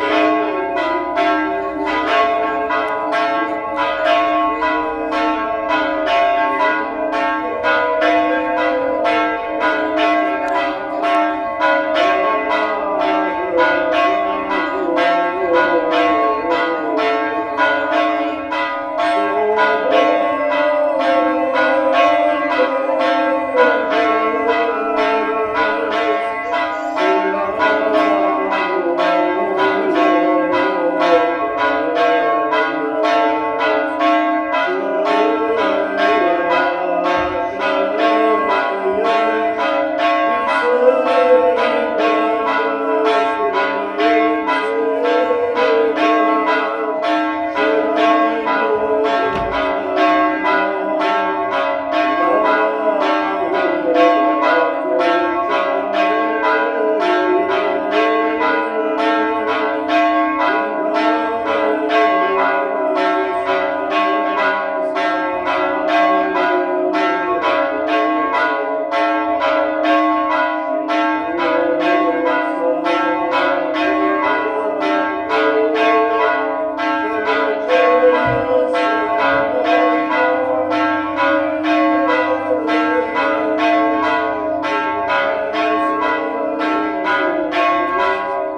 • church bells sound with priest singing.wav
church_bells_sound_with_priest_singing_YWt.wav